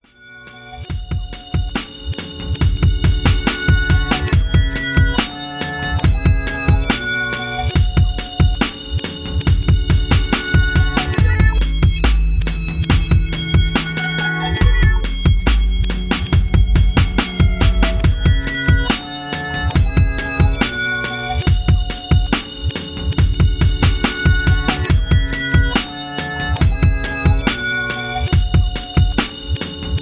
R&B Summer Banger